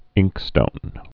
(ĭngkstōn)